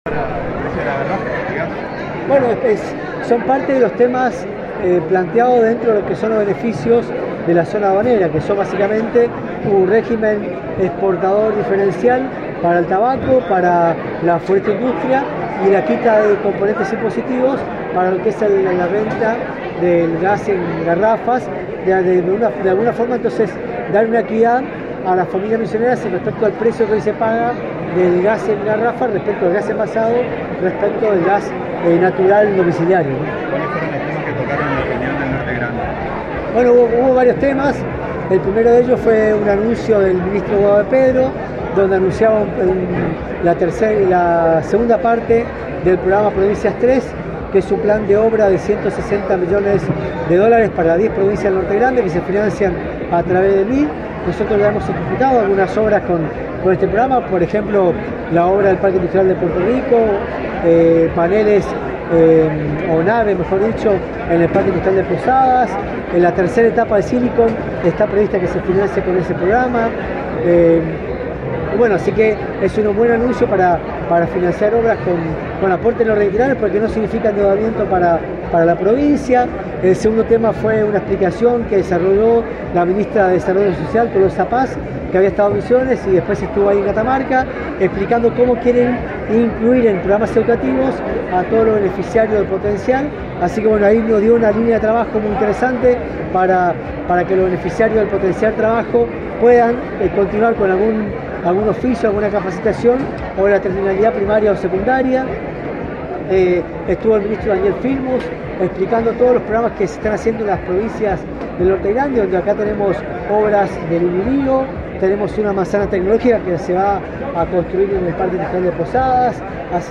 En diálogo exclusivo con la Agencia de Noticias Guacurarí, el Ministro de Hacienda de Misiones, Contador Adolfo Safrán, explicó que entre las medidas económicas que tendrá la Zona Aduanera Especial para Misiones habrá un precio especial para el gas en garrafas, además de un dólar especial para la exportación de productos de la Tierra Colorada.